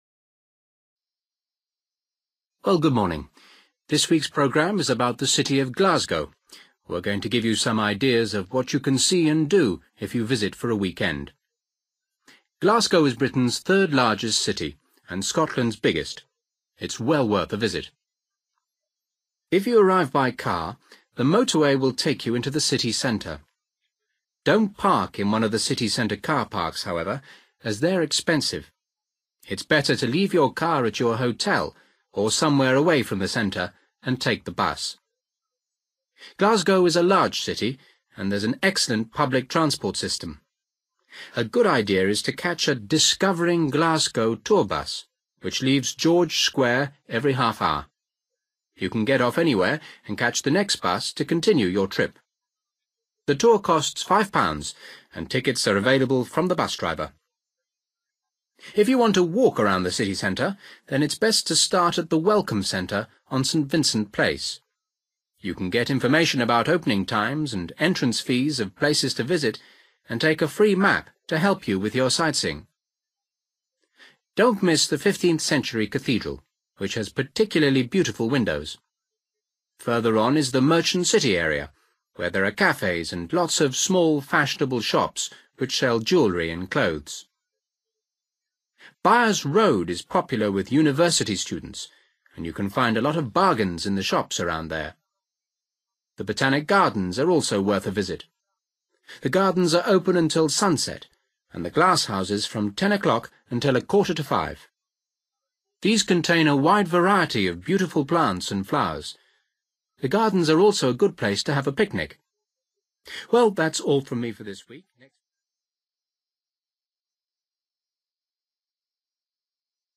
You will hear a radio programme giving you information about the city of Glasgow.